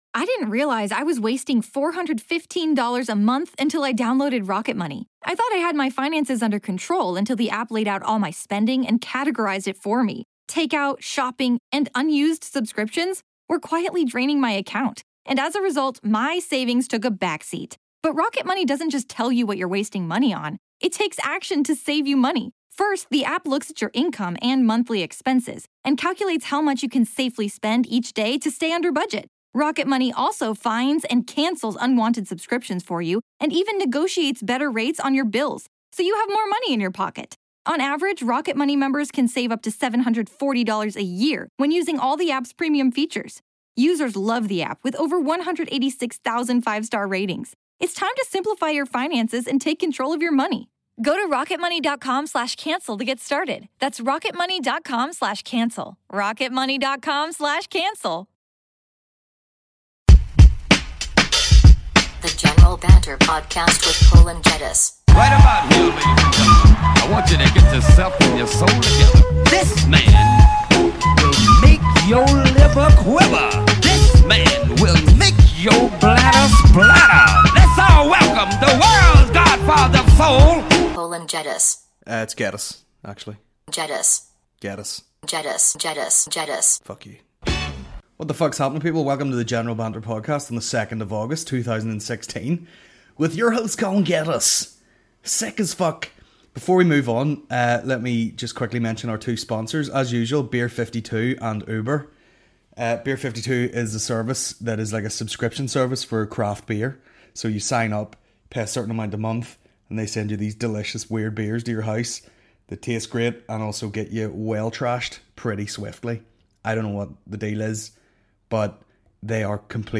a Comedy podcast